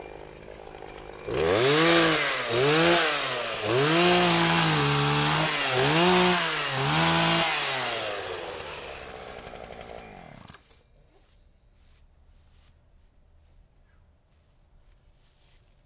电锯 " 电锯闲置和附近的锯子
描述：电锯闲置和锯切附近.flac
标签： 闲置 附近 电锯
声道立体声